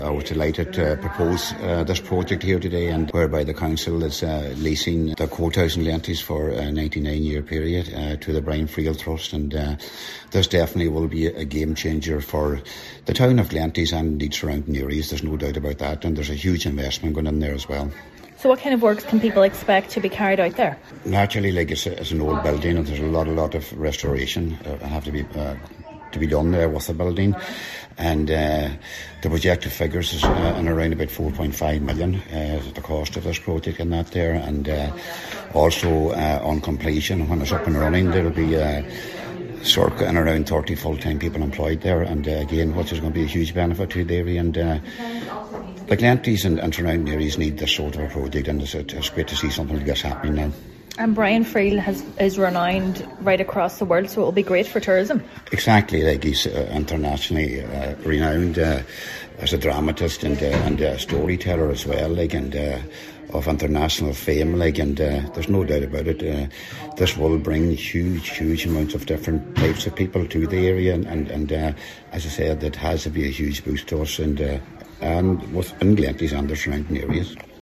Councillor Anthony Molloy says the development will deliver significant social and economic benefits for Glenties and surrounding areas: